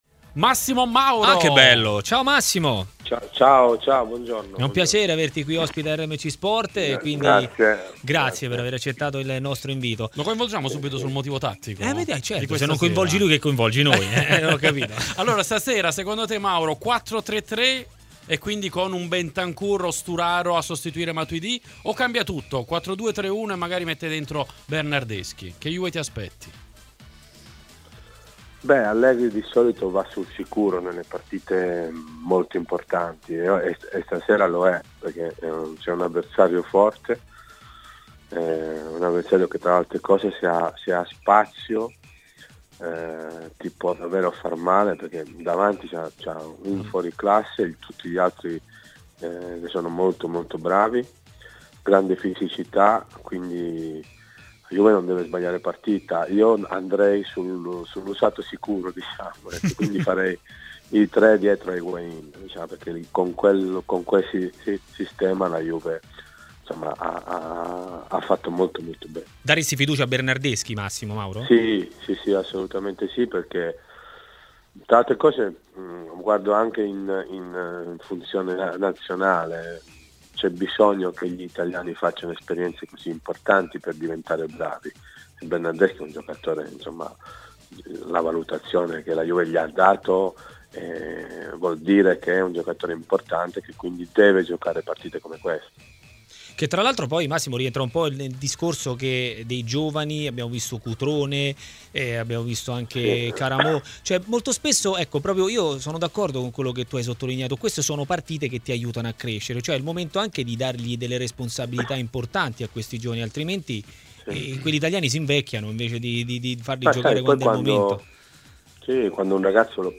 L'intervento di Massimo Mauro, opinionista di Sky Sport ed ex giocatore di Juventus e Napoli, in diretta su RMC Sport, durante la trasmissione pomeridiana 'Maracanà':